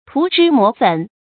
注音：ㄊㄨˊ ㄓㄧ ㄇㄛˇ ㄈㄣˇ
涂脂抹粉的讀法